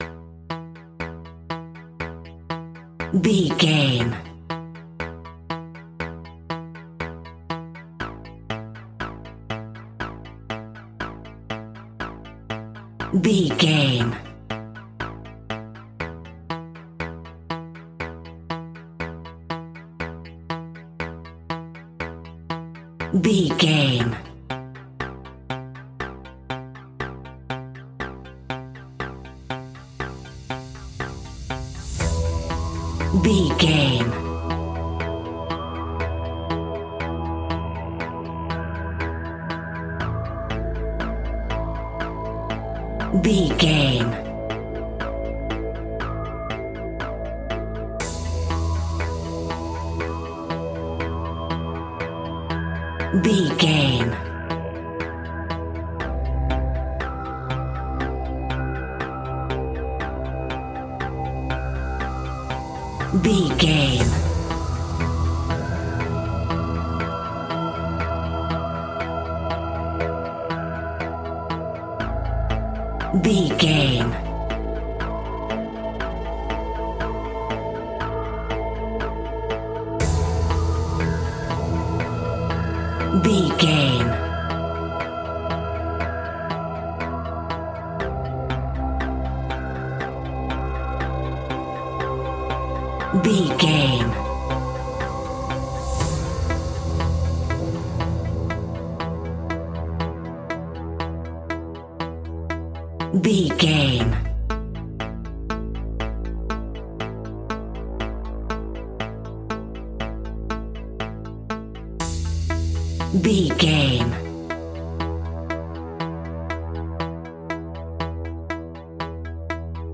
Aeolian/Minor
scary
tension
ominous
dark
haunting
eerie
synthesizer
drum machine
ticking
electronic music
electronic instrumentals